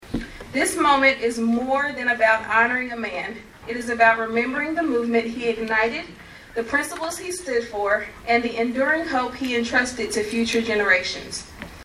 Monday morning, the community gathered at HCC to honor Dr. Martin Luther King Jr.’s vision for justice and equality.